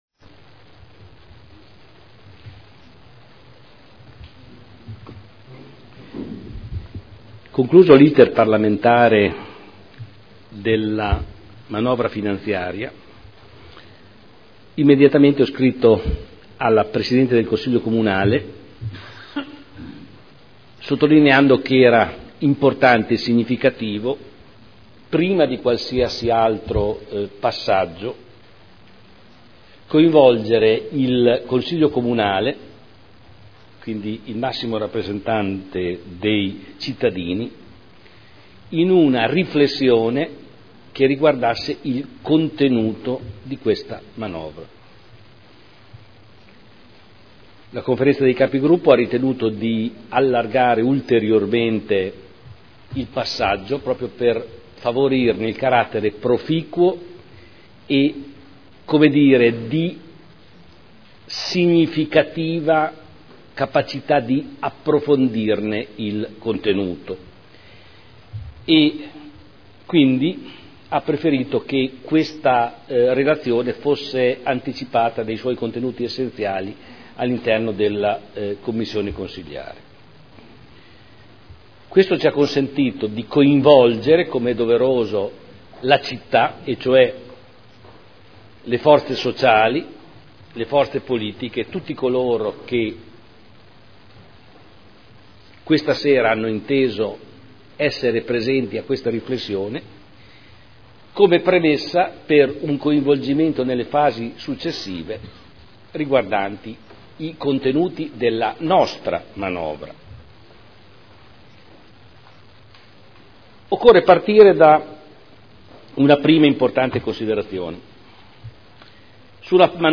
Seduta del 26 settembre 2011 Ricadute della manovra del Governo sul Bilancio del Comune di Modena – Comunicazione del Sindaco